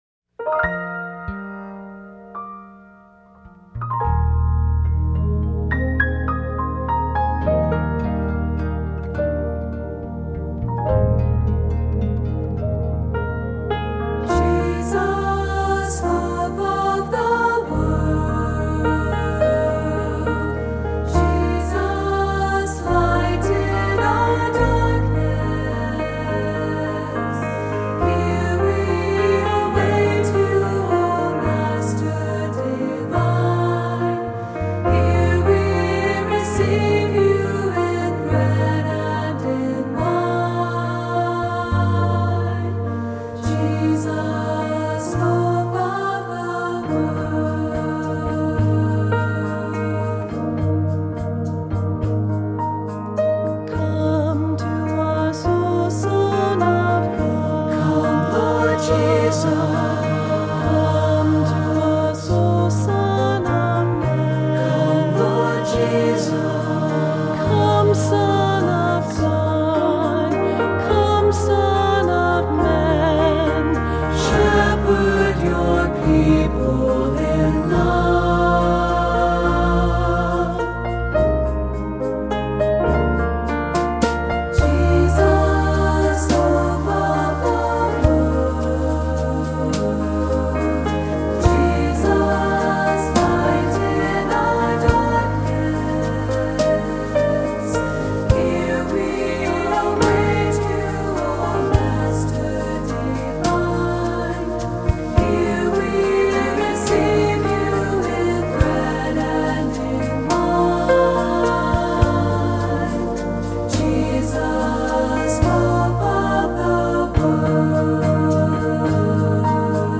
Voicing: SATB; Descant; Cantor; Assembly